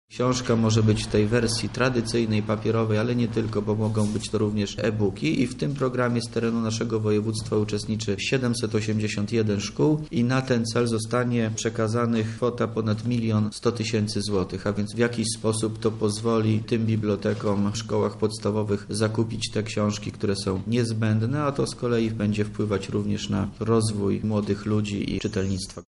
– tłumaczy Wojciech Wilk, wojewoda lubelski